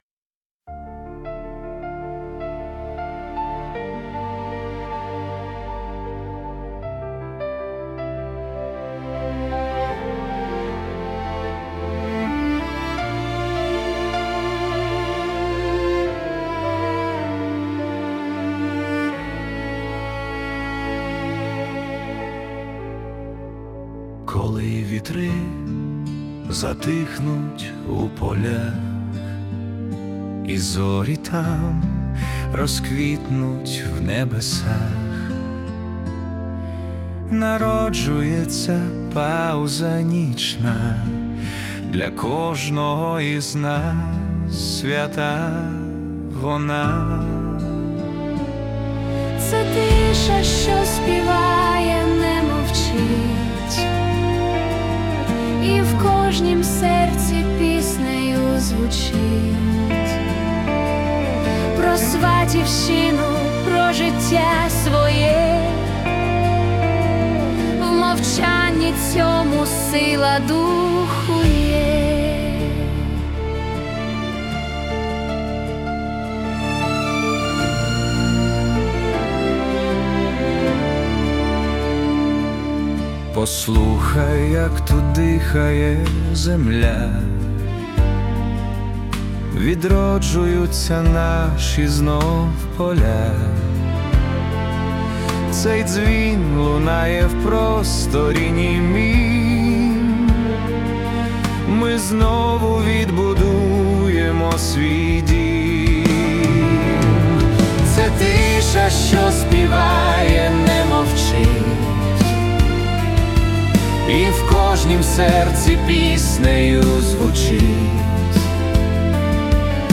Atmospheric Ballad / Cinematic